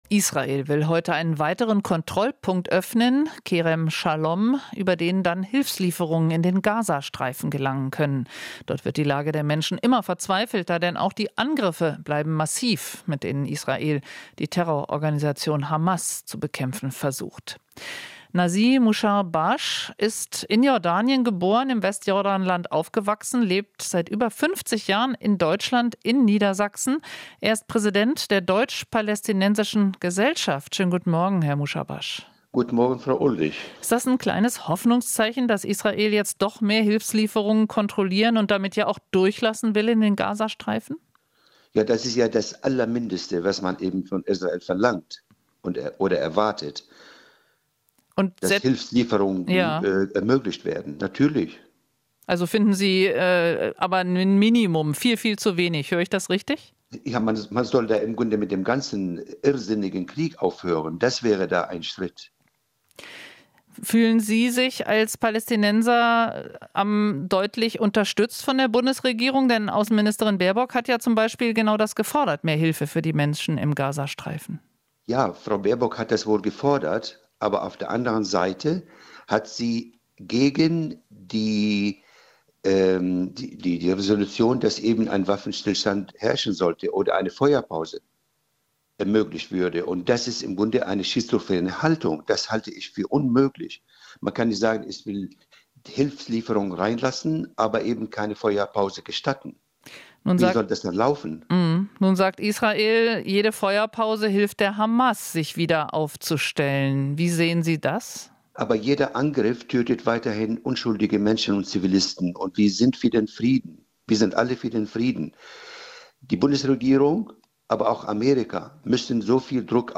Interview - Deutsch-Palästinensische Gesellschaft: "Irrsinniger Krieg" muss aufhören